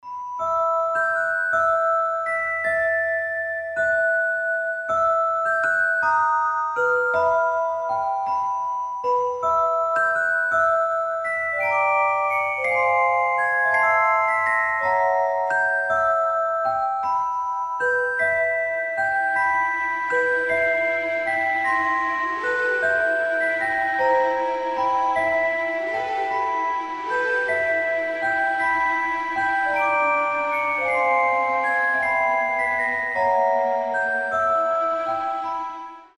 • Качество: 320, Stereo
спокойные
без слов
красивая мелодия
медленные
из фильмов
Музыкальная шкатулка
колыбельные